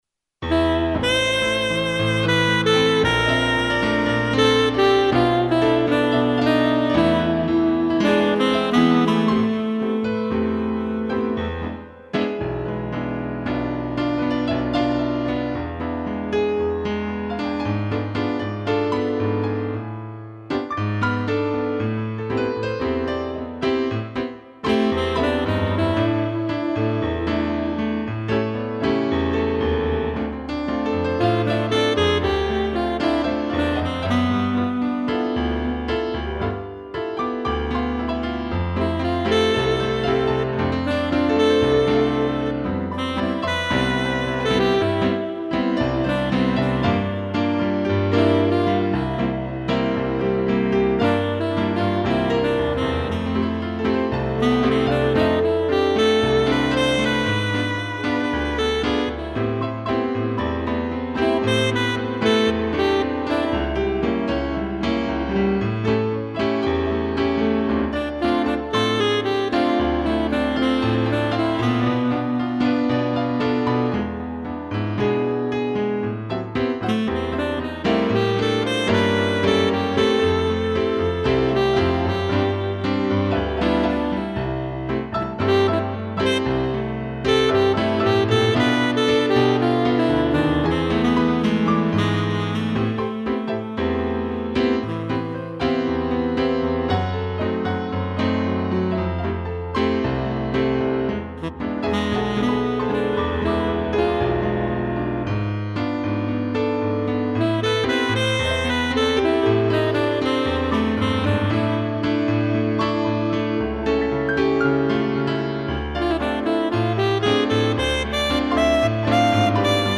2 pianos e sax
instrumental